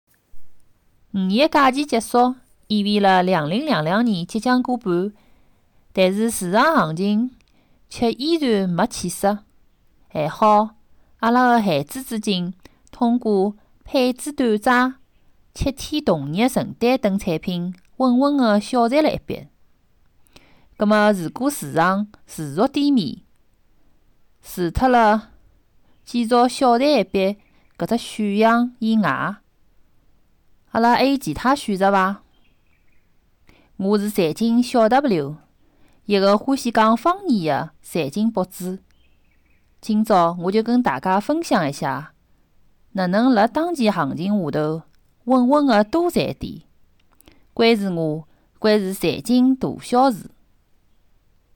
中老年女声